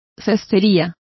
Complete with pronunciation of the translation of wickerwork.